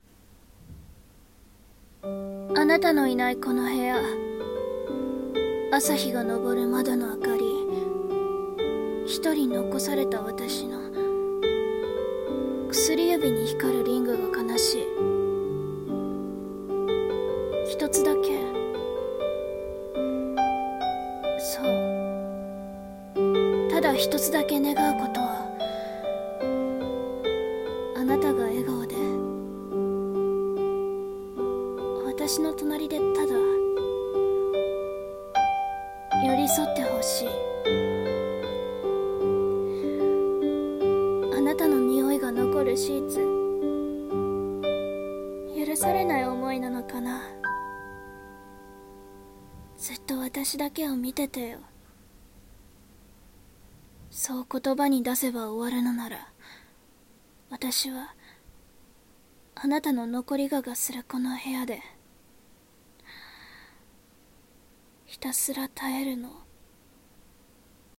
【声劇】残り香